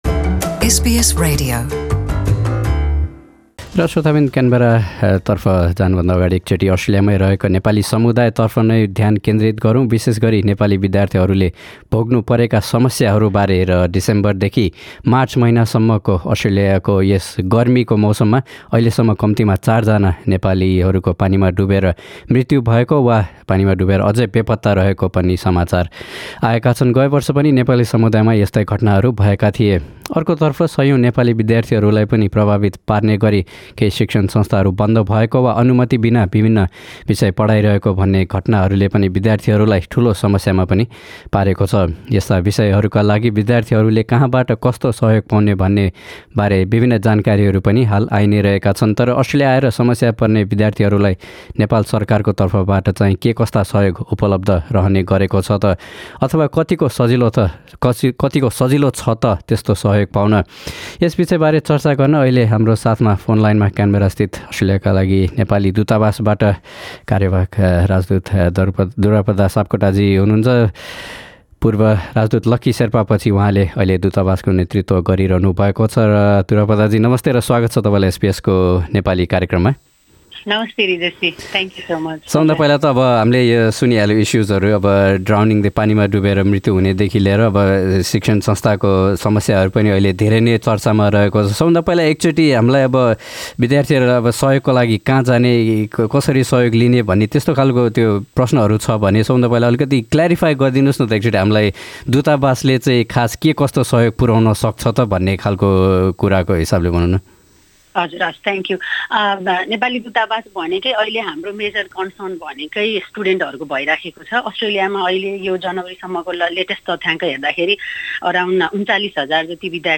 Nepal's acting ambassador to Australia Durapada Sapkota (L) spoke to SBS Nepali about the help available to resolve students' problems in Australia.